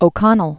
(ō-kŏnəl), Daniel Known as "the Liberator." 1775-1847.